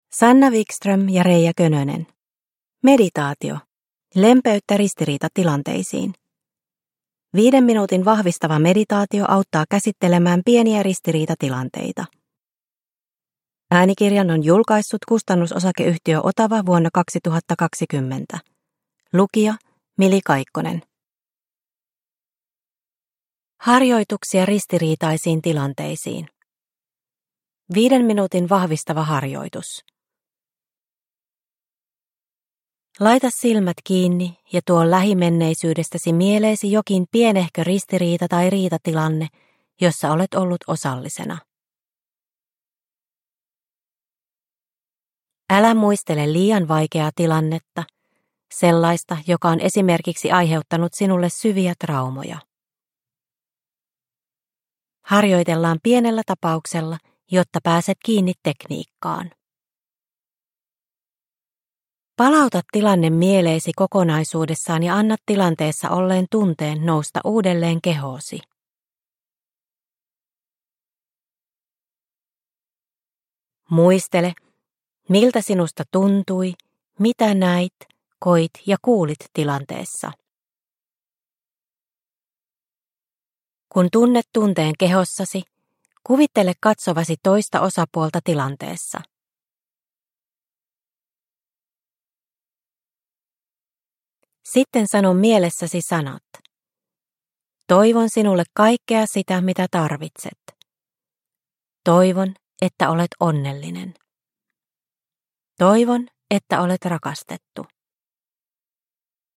Meditaatio - Lempeyttä ristiriitatilanteisiin – Ljudbok – Laddas ner